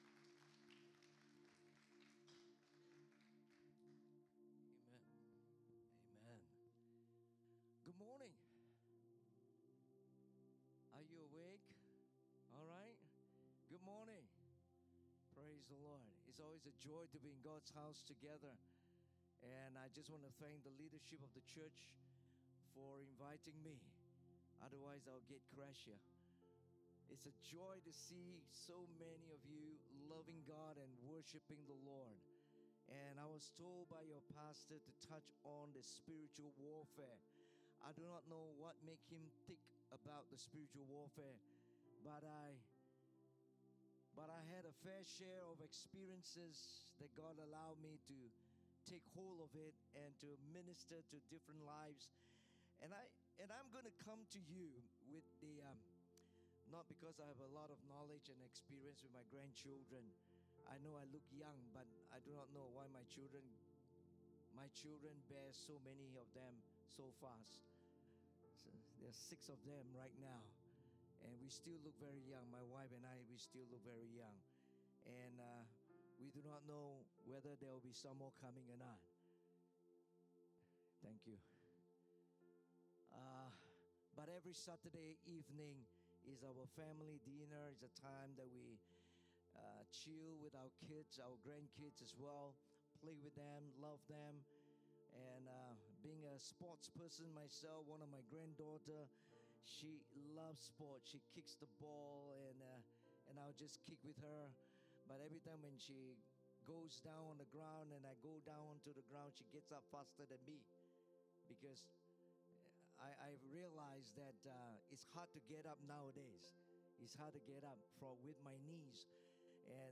English Sermons | Casey Life International Church (CLIC)
Guest Speaker